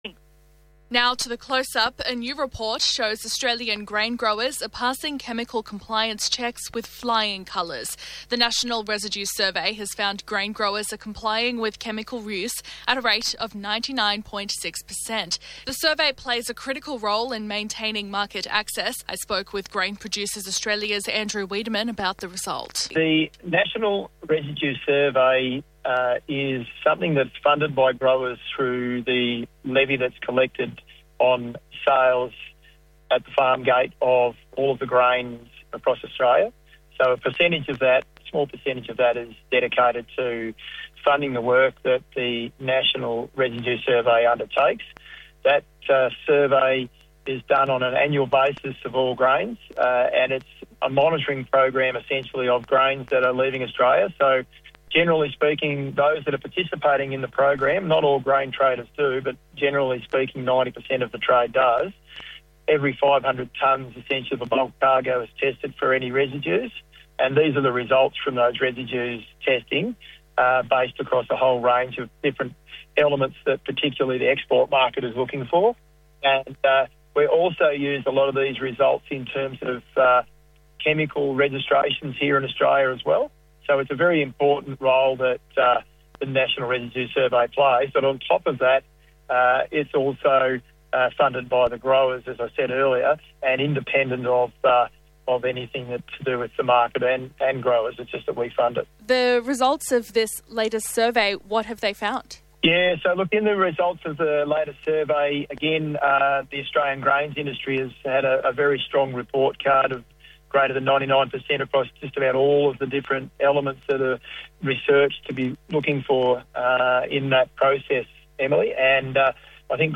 2WEB OUTBACK RADIO | Grain producers excel in chemical compliance